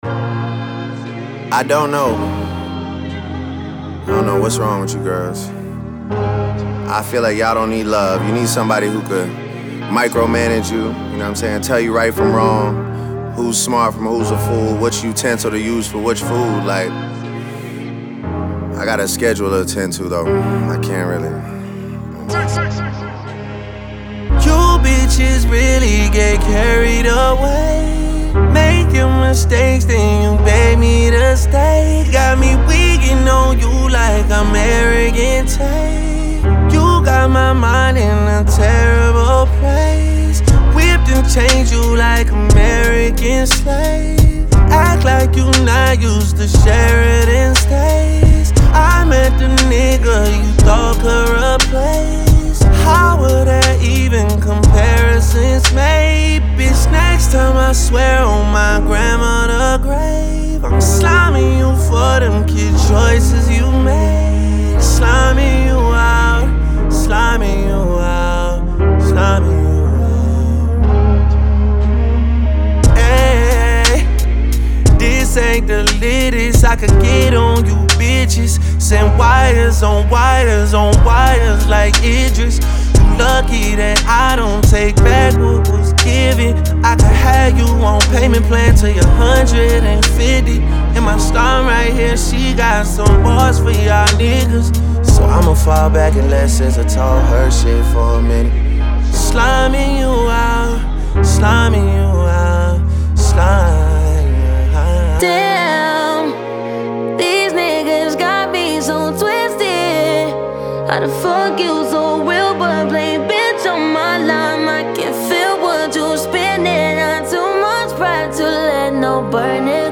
rap رپ خارجی